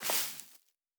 Tall_Grass_Mono_04.wav